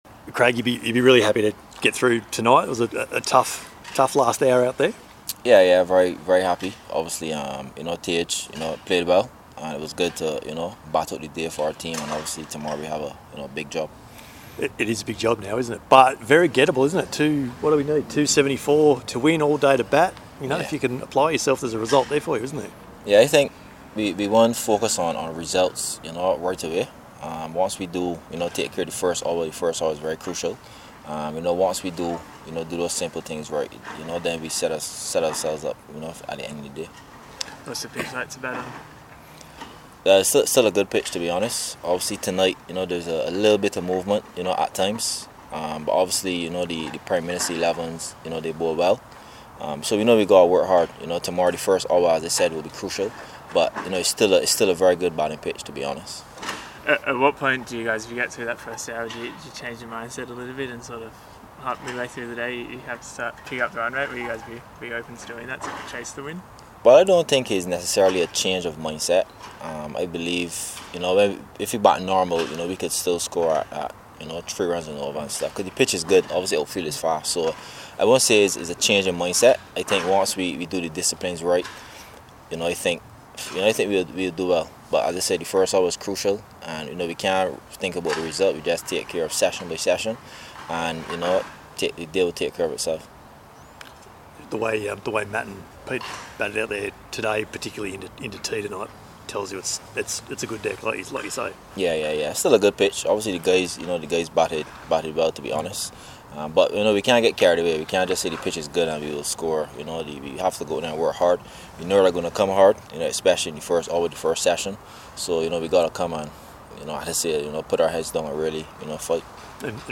Kraigg Brathwaite speaks on Day 3 of Prime Minister's XI vs West Indies